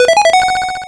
RedCoin1.wav